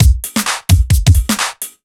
OTG_Kit 3_HeavySwing_130-B.wav